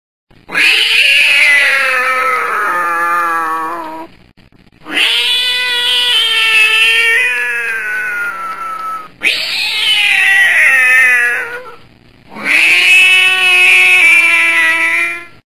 mjayu.mp3